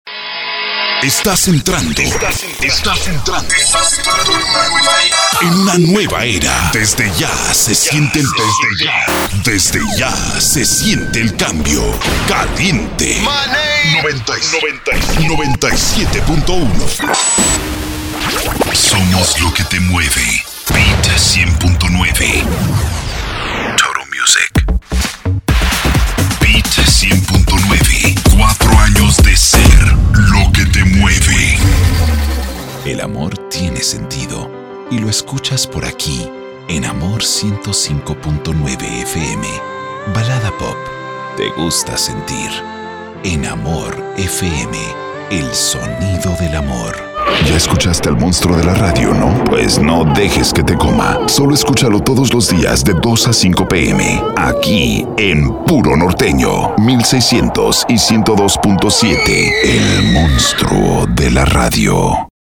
Demo Reel Id's de Radio